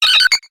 Grito de Togetic.ogg
Grito_de_Togetic.ogg.mp3